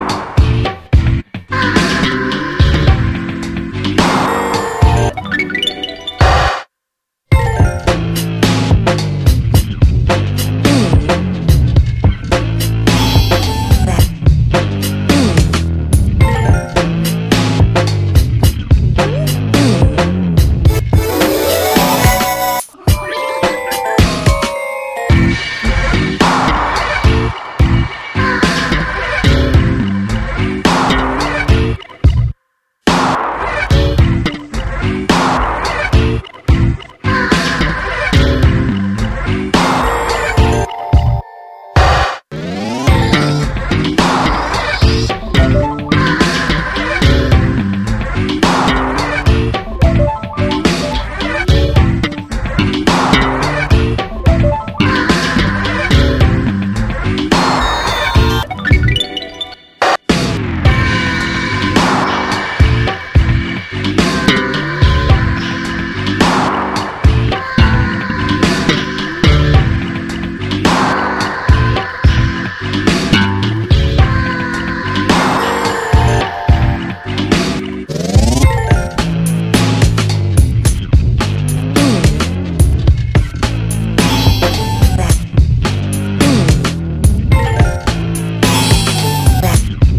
キャッチーかつポップ、室内でも屋外でも気分が上がって心踊れる極上のトラック満載のアルバムです。』